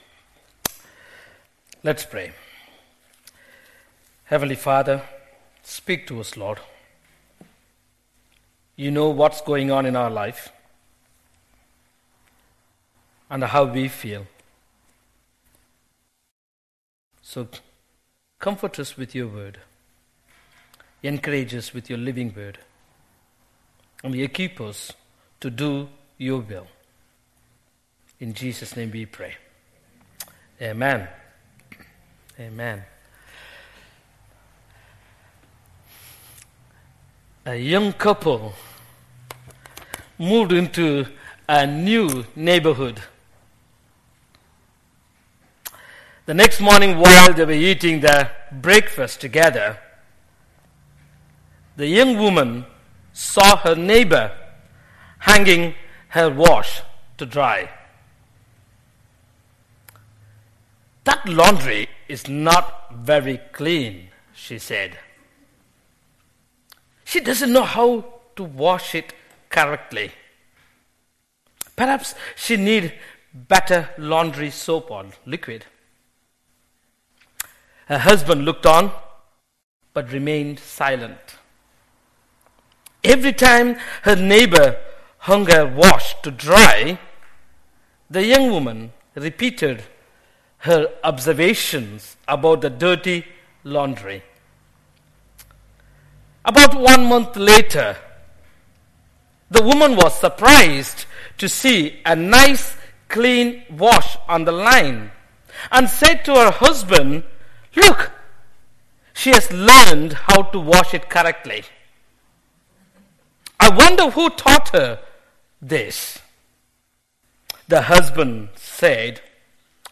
An audio version of the sermon is also available.
10-27-sermon.mp3